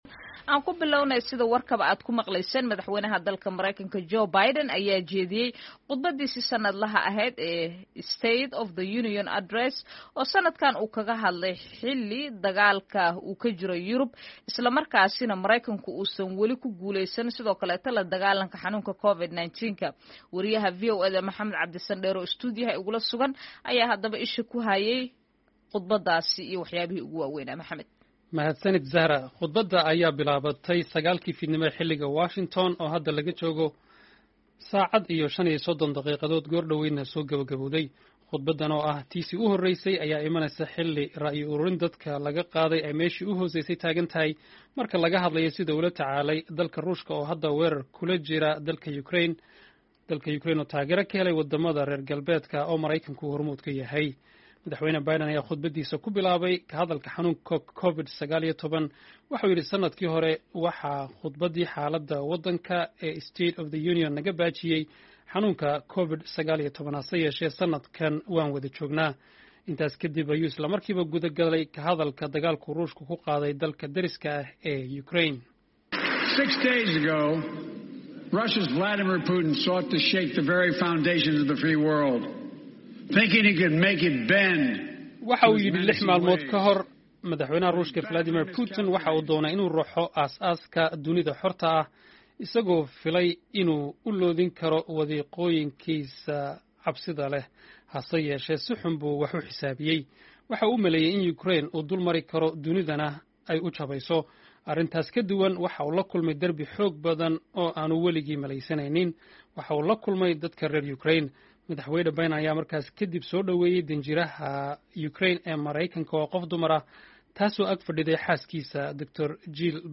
Khudbaddii Madaxweyne Biden